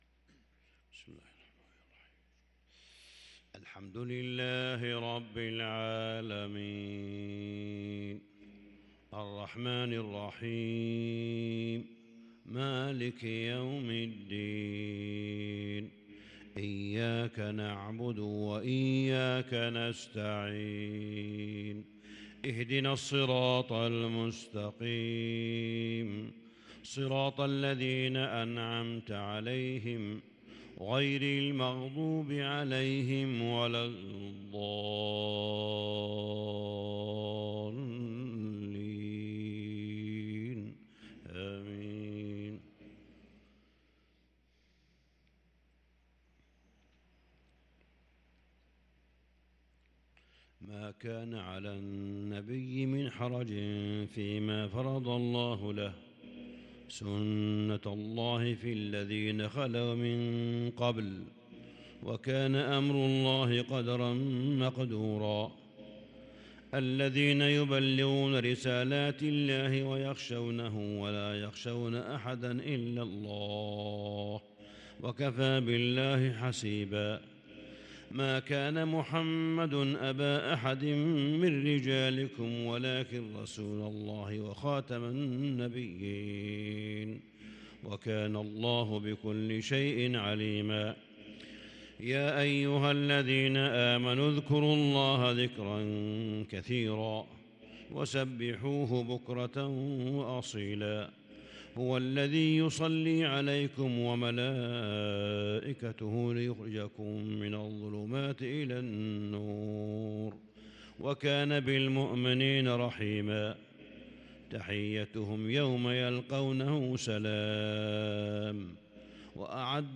صلاة الفجر للقارئ صالح بن حميد 27 صفر 1444 هـ
تِلَاوَات الْحَرَمَيْن .